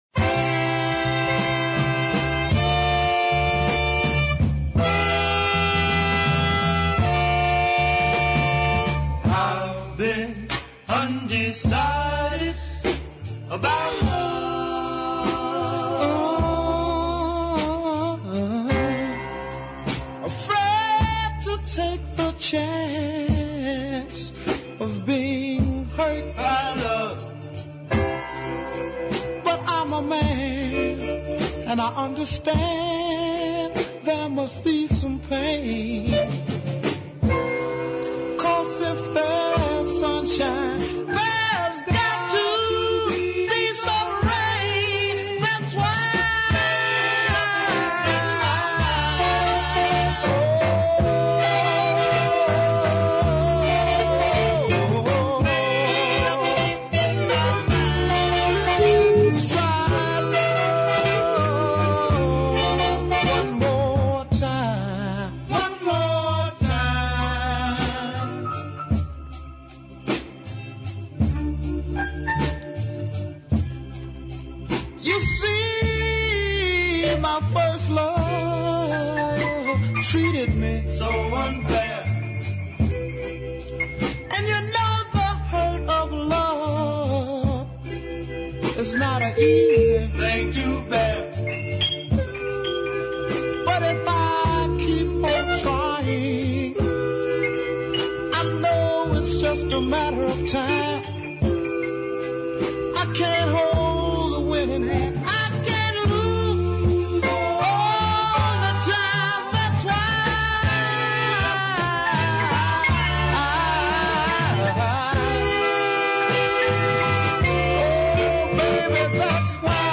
is a super group ballad